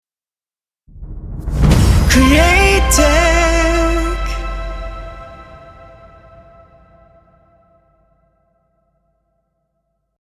AUDIO LOGO